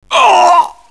mil_pain3.wav